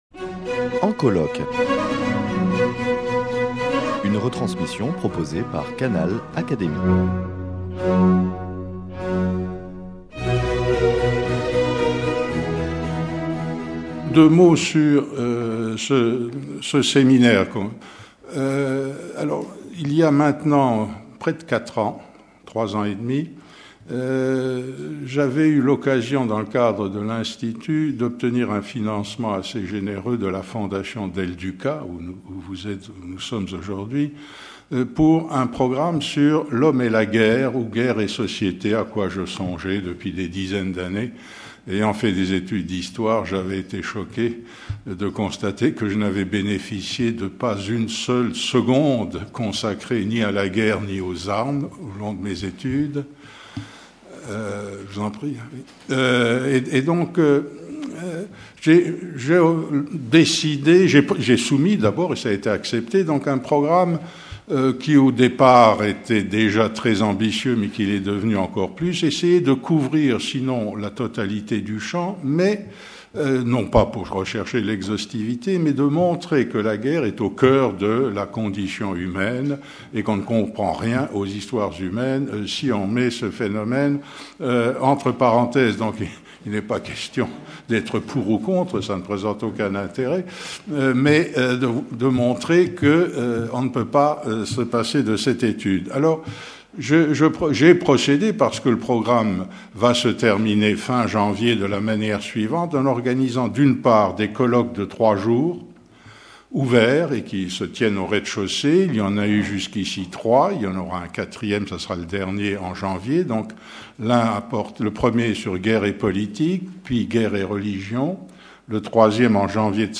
Introduction de Jean Baechler, membre de l’Académie des sciences morales et politiques, prononcée le 18 novembre 2015 lors des journées d’étude « Guerre et milieux naturels » organisées dans le cadre du programme de recherche « Guerre et société » soutenu par la Fondation Simone et Cino del Duca et l’Académie des sciences morales et politiques.